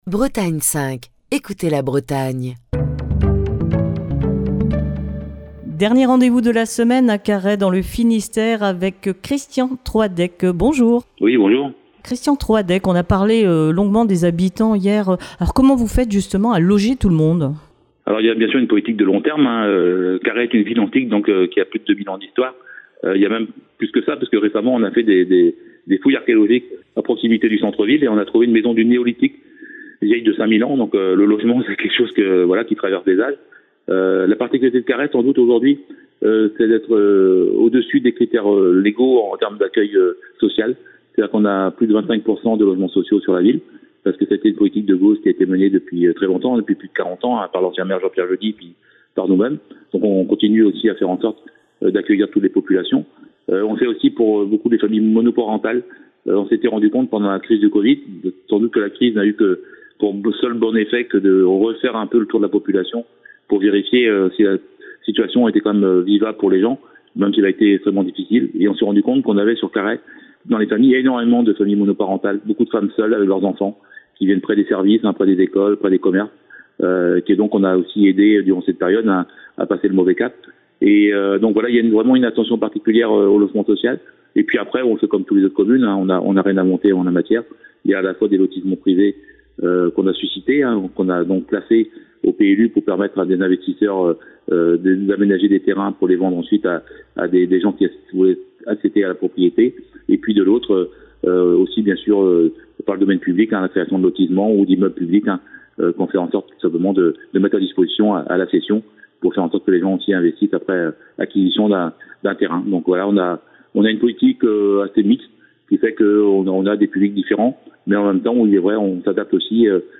est au téléphone avec Christian Troadec, le maire de Carhaix, qui vous propose de découvrir sa ville et les divers aspects de son quotidien d'élu.